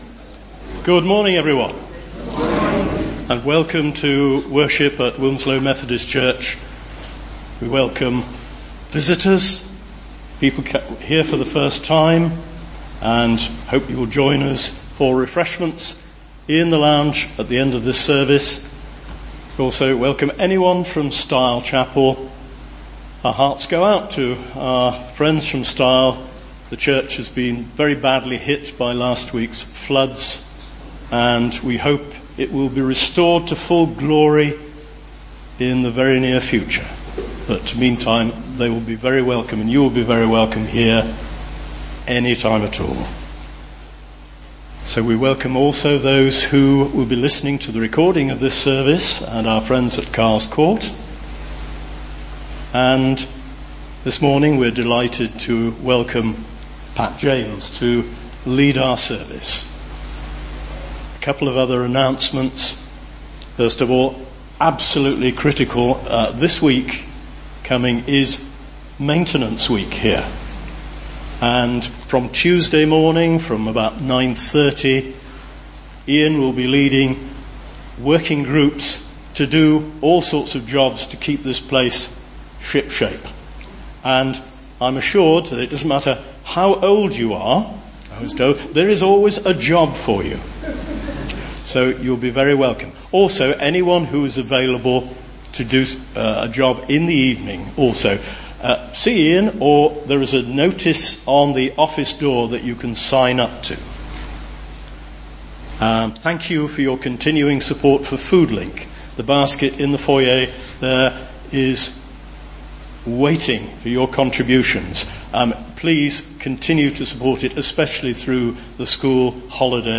2019-08-04 Morning Worship
Genre: Speech.